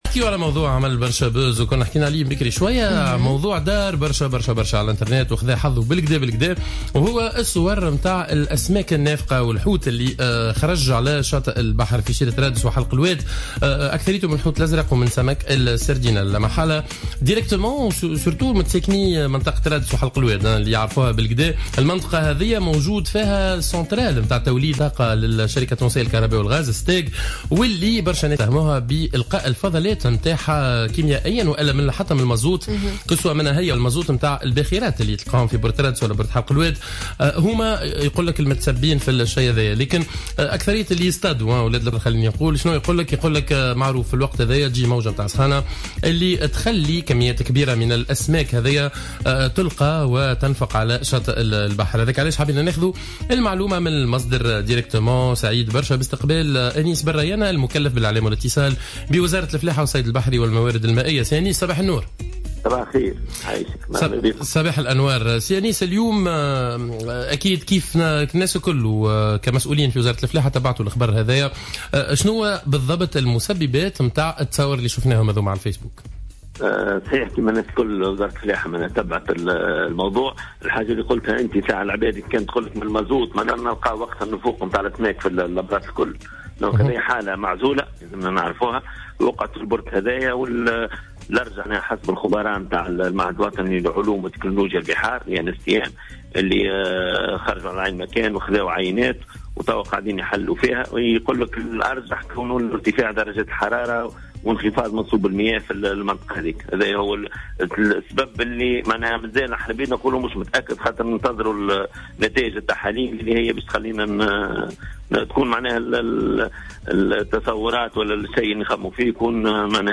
في تصريح للجوهرة أف أم اليوم الاثنين 07 سبتمبر 2015 في برنامج صباح الورد